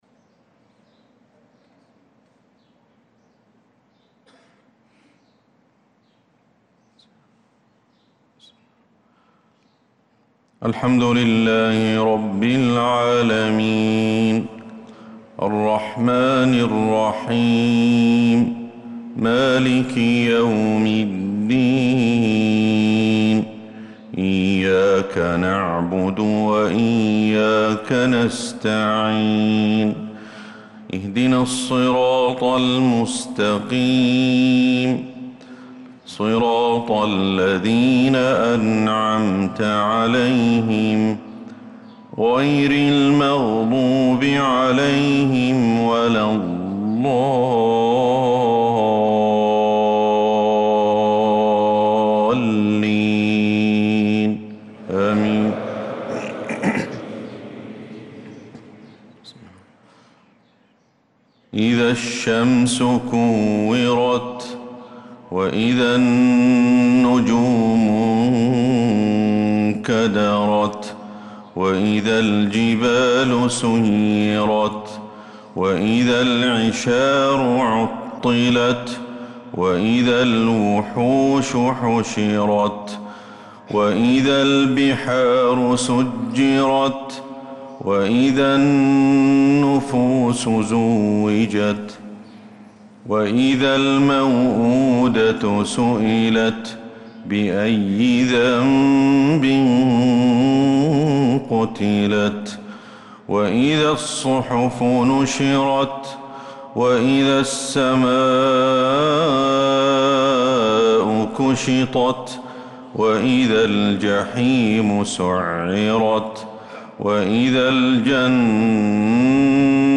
صلاة الفجر للقارئ أحمد الحذيفي 25 ذو القعدة 1445 هـ